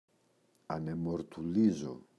ανεμοτουρλίζω [anemotu’rlizo] – ΔΠΗ